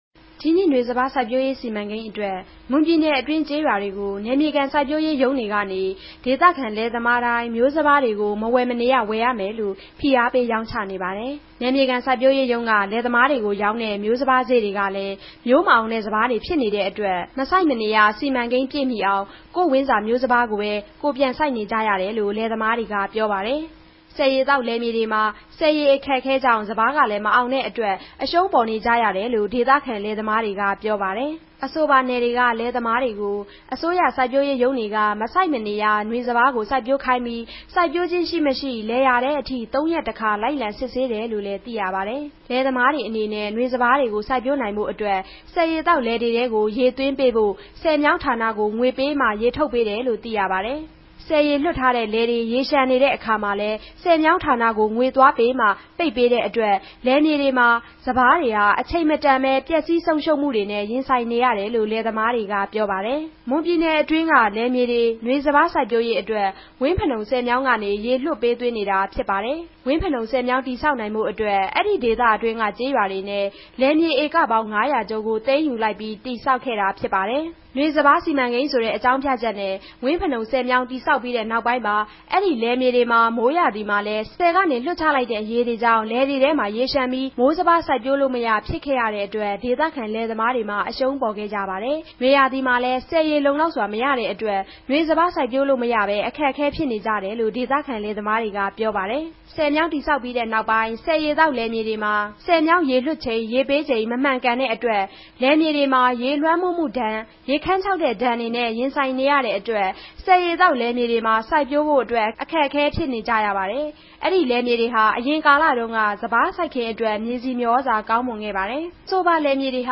ဒေသခံလယ်သမားတေနြဲႛ ဆက်သြယ်မေးူမန်းခဲ့ပၝတယ်၊၊